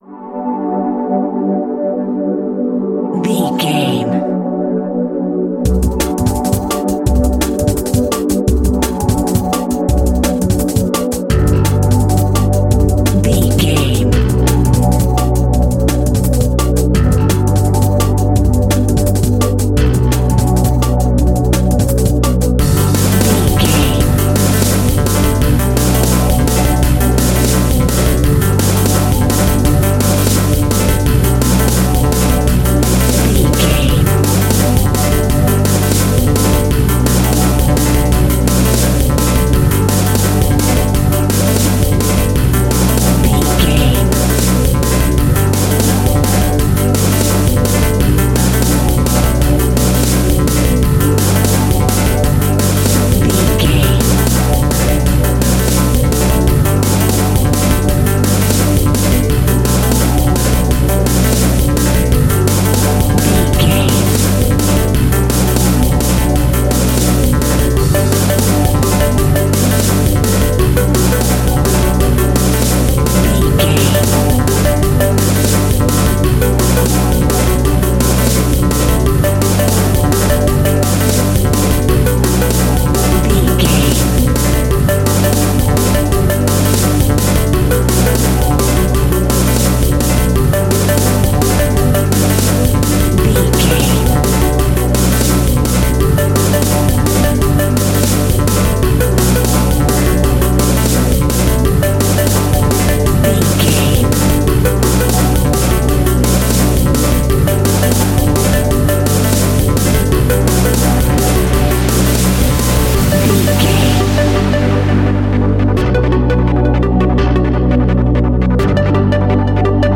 Aeolian/Minor
Fast
futuristic
hypnotic
industrial
dreamy
frantic
dark
synthesiser
drum machine
sub bass
synth leads
synth bass